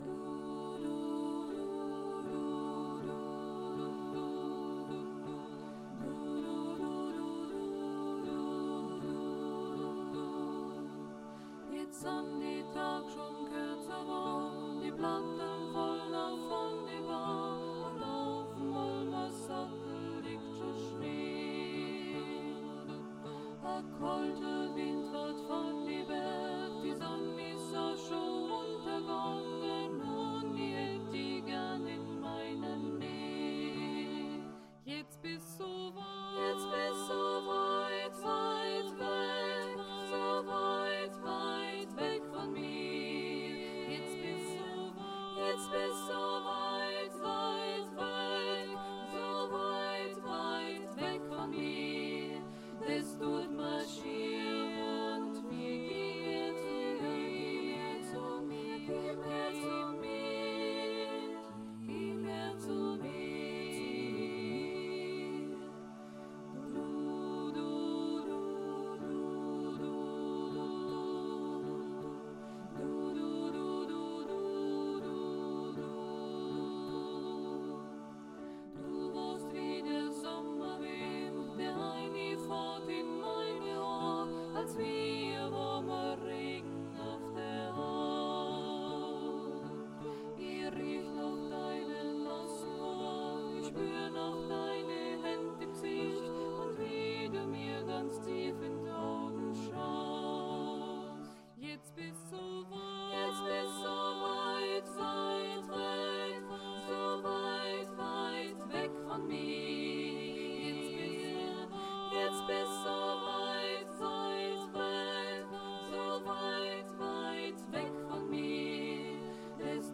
Alle Stimmen